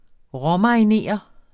Udtale [ ˈʁʌ- ]